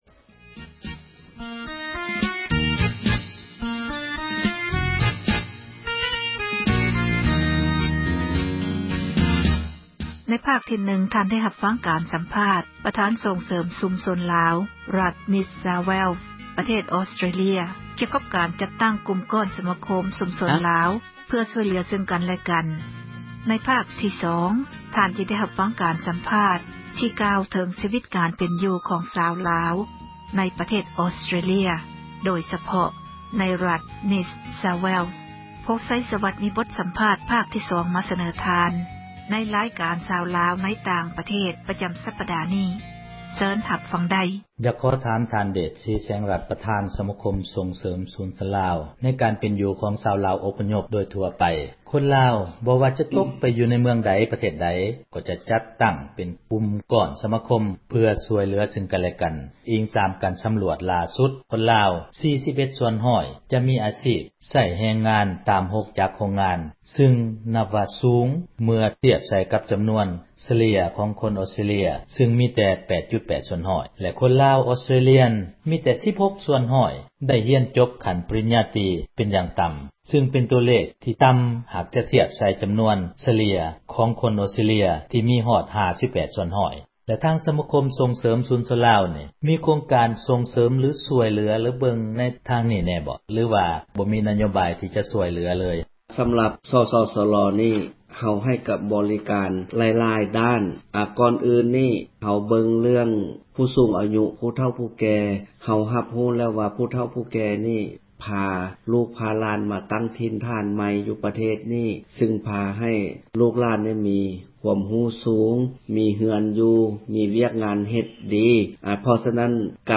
ໃນພາກທີ 2 ທ່ານຈະໄດ້ ຮັບຟັງ ການສໍາພາດ ທີ່ກ່າວເຖິງ ຊີວິດ ການເປັນຢູ່ ຂອງ ຊາວລາວ ໃນປະເທດ ອອສເຕຣເລັຍ ໂດຍສະເພາະ ໃນຣັຖ ນິສຊາວແວັສ. ສເນີໂດຍ